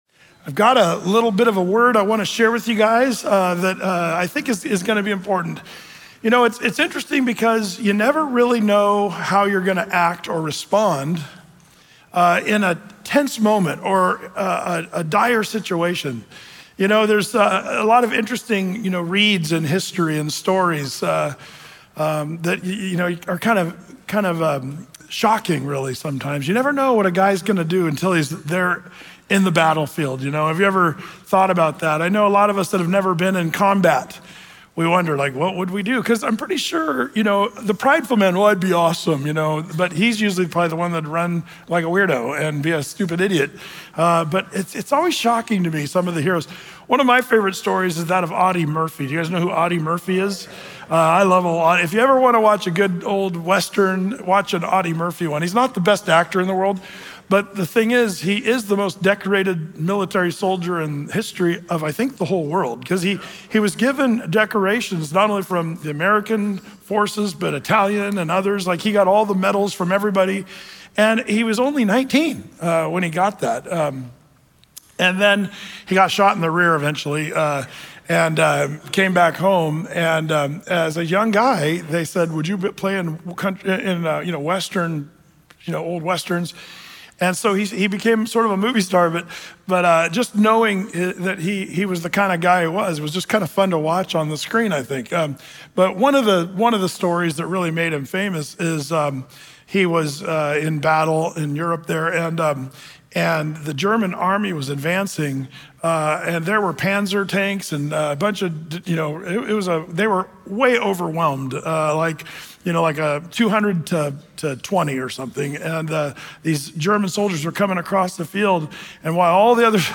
Through-the-Bible teaching